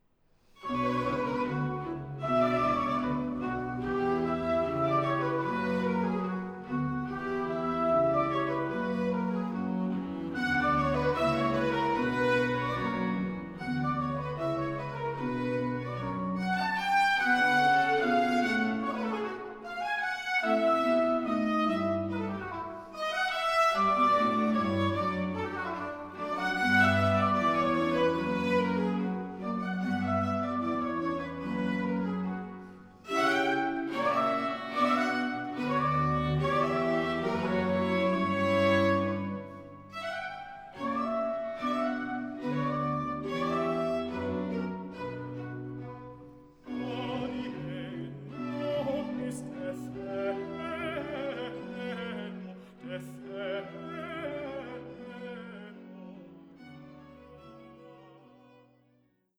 una de las obras de referencia del Barroco musical español.